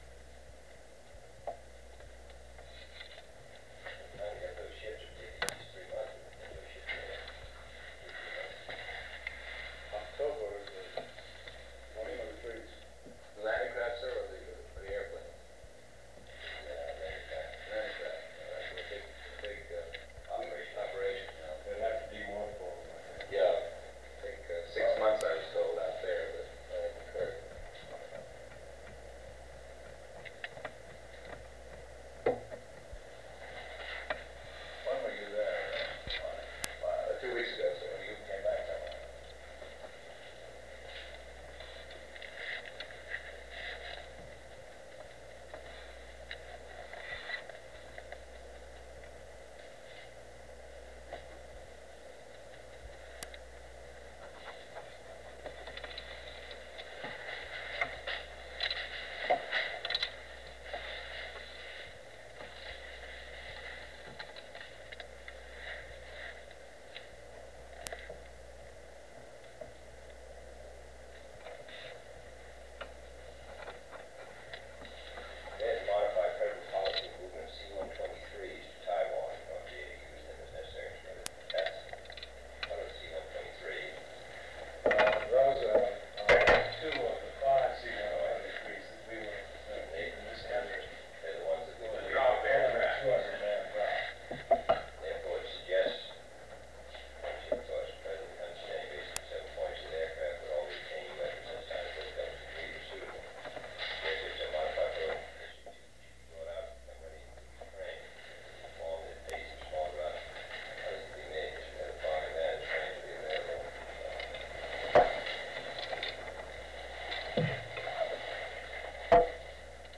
Secret White House Tapes | John F. Kennedy Presidency Meeting on China and the Congo Rewind 10 seconds Play/Pause Fast-forward 10 seconds 0:00 Download audio Previous Meetings: Tape 121/A57.